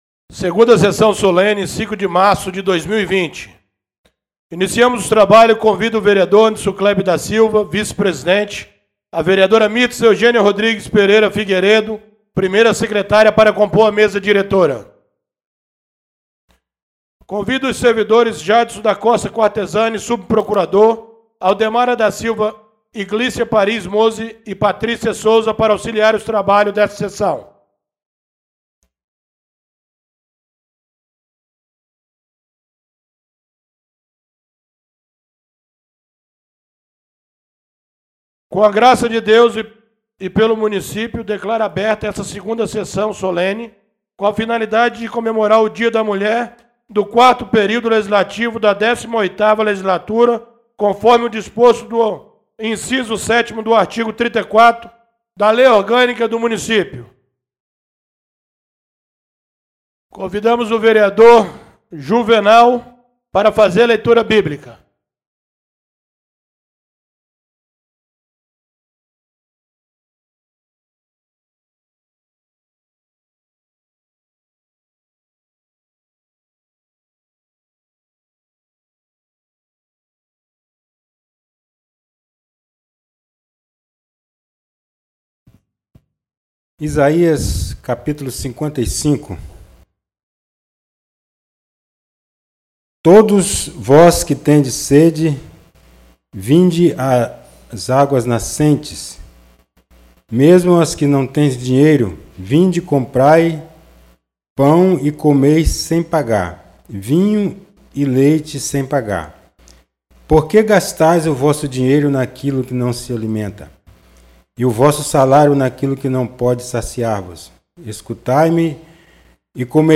2ª Sessão Solene do dia 05 de março de 2020